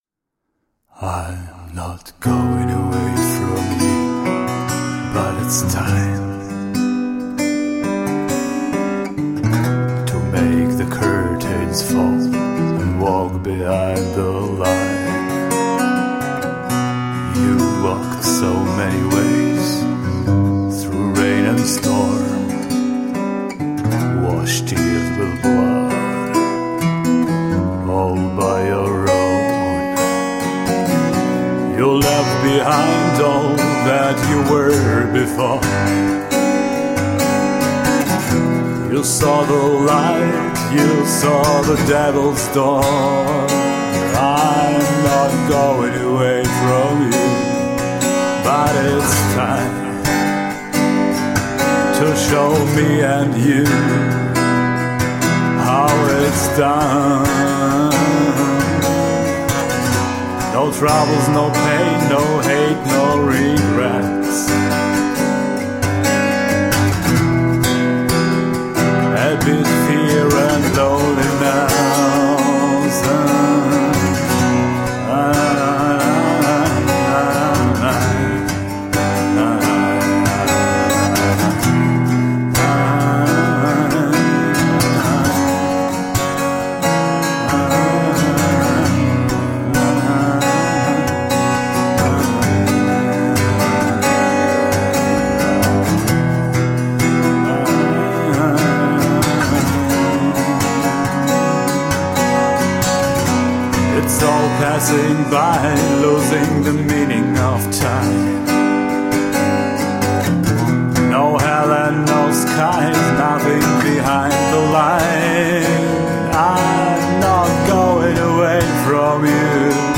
Pure soulful and raw acoustic songwriter.
Tagged as: Alt Rock, Darkwave, Goth, Indie Rock